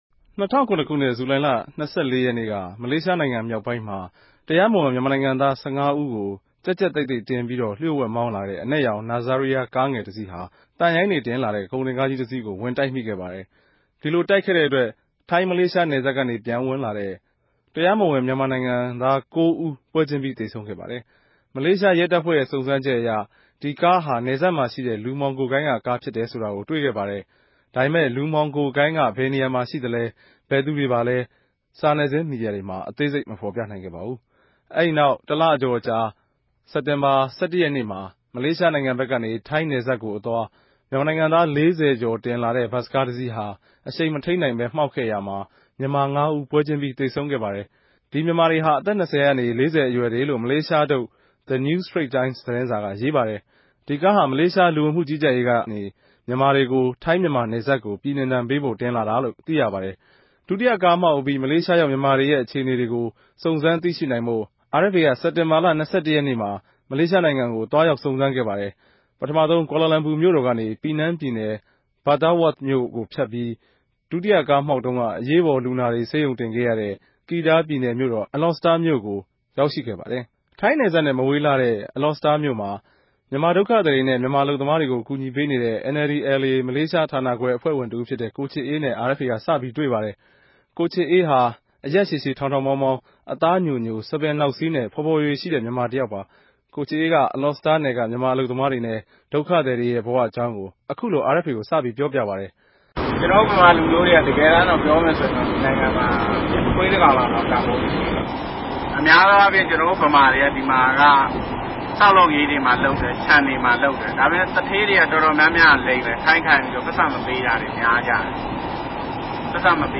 ဒီအေုကာင်းတေကြို RFAက အပိုင်း ၆ ပိုင်းနဲႛ ၆ ရက်ထုတ်လြင့်ထား္ဘပီး အသံလြင့်ဆောင်းပၝးအူဖစ် တင်ဆက်ထားပၝတယ်။